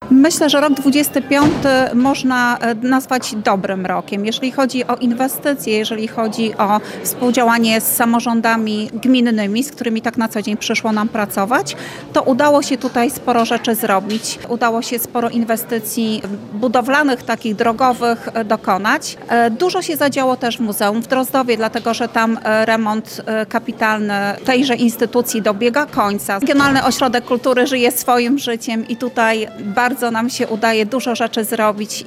W Starostwie Powiatowym w Łomży odbyło się w poniedziałek (22.12) spotkanie wigilijne.
Jak podkreśla wicestarosta Anna Gawrych minione 12 miesięcy były bardzo intensywne: